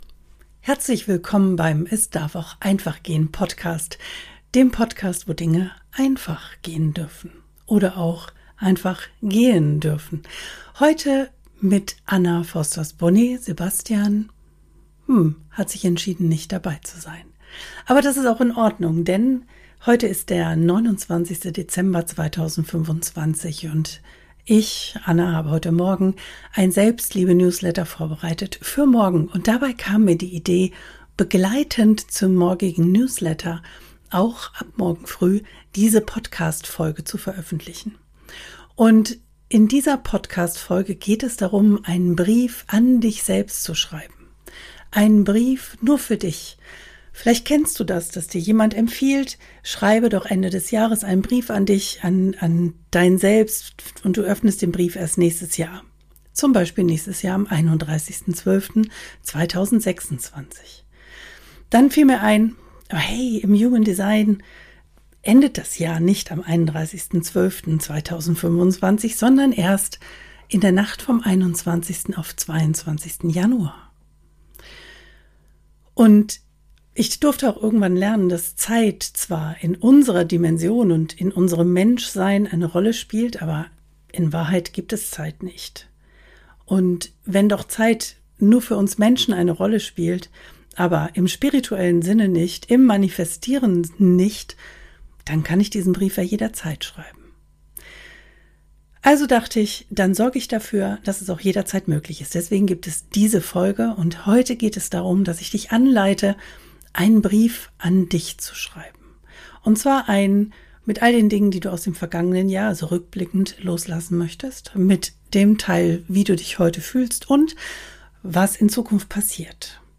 Beschreibung vor 3 Monaten In dieser Folge lade ich dich zu einer sanften, geführten Schreibübung ein: einem Brief an dich selbst.